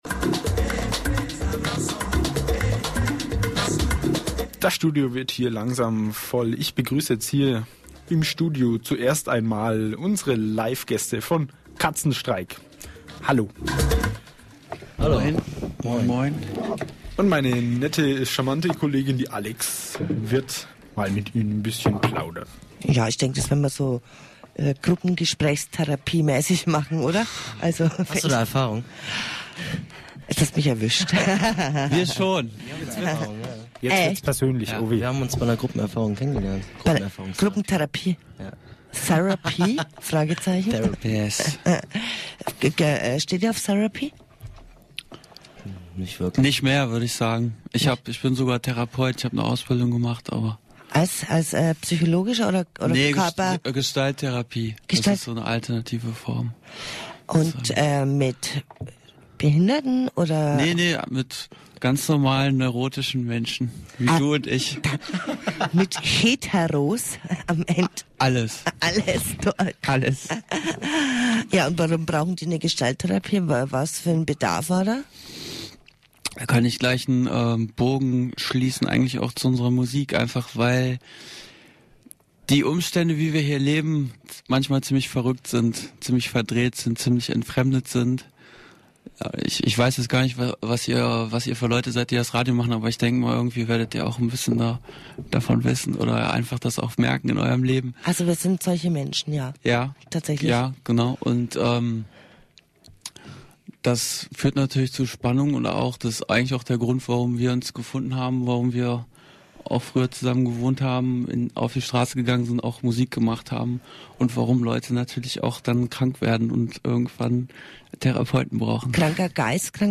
Interviews
radio-z-interview2.mp3